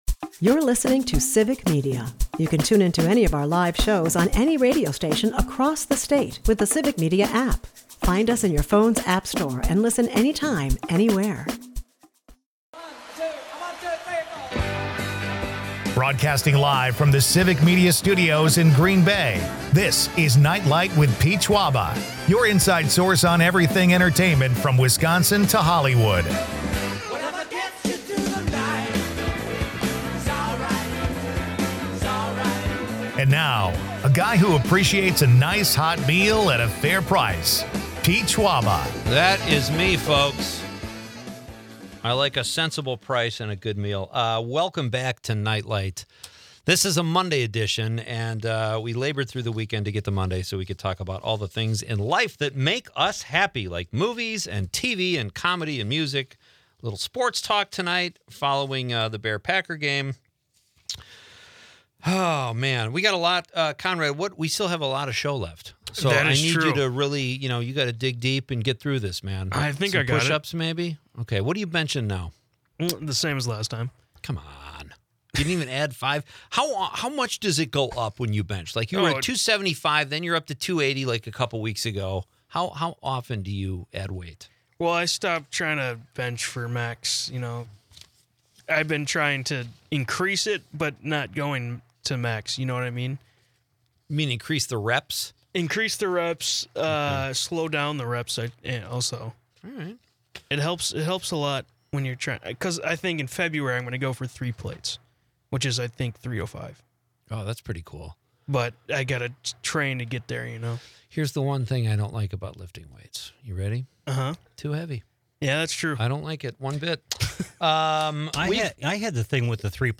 Listeners engage in a spirited debate over the best football movies, with 'Rudy' and 'Brian's Song' taking center stage. A fun and festive episode packed with laughs and nostalgia.